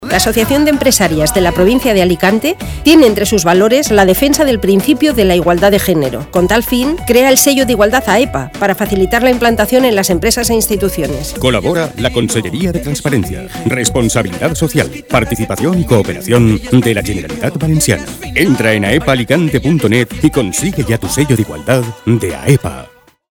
Mensaje en forma de cuña: